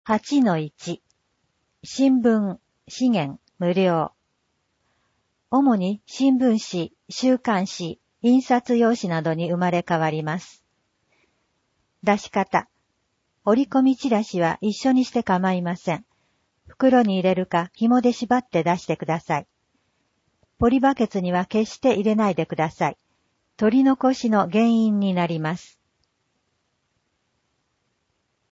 音訳版「生活ごみと資源物の出し方」パンフレット